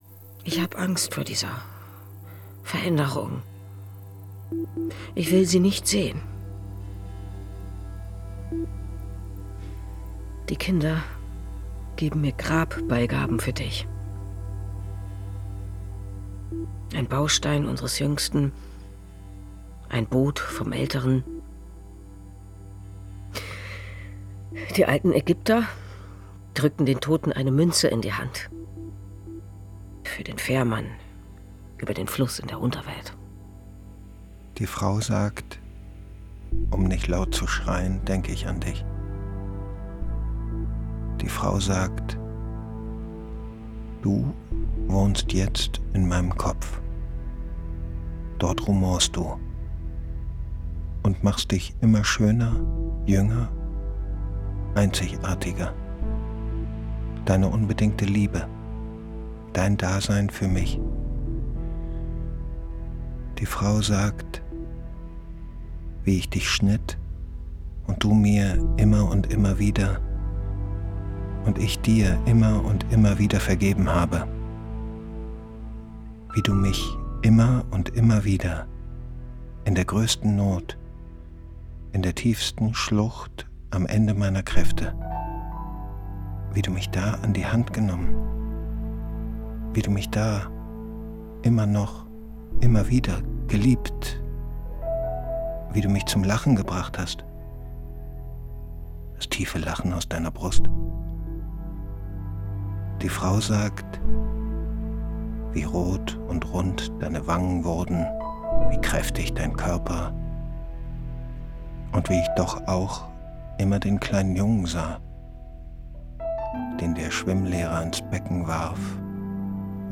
Radio | Hörspiel
Es gibt Phasen, in denen die Frau somnambul wirkt, dann wieder ist sie sehr klar.